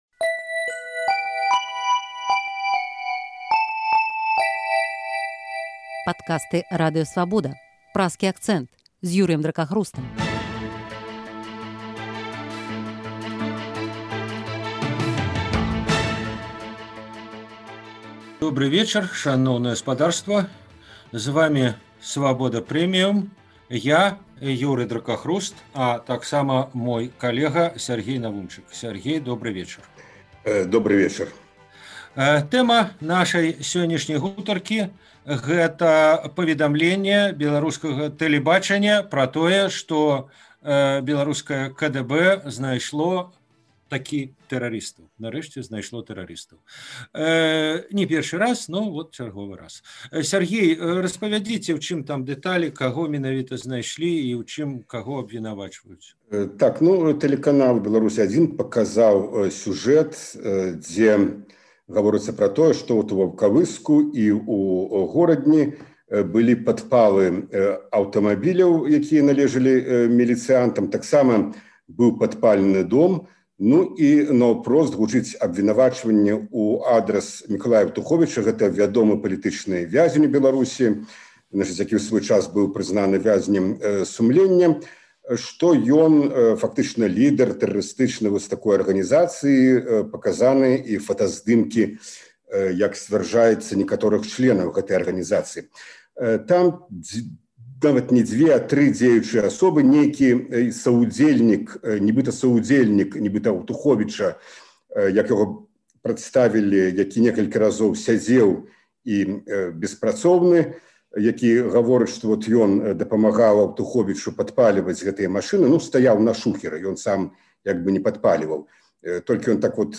Ці стала гэтая справа праявай радыкалізацыі беларускага грамадзтва і хто за гэта адказны? Гэтыя тэмы ў Праскім акцэнце абмяркоўваюць палітычныя аглядальнікі Свабоды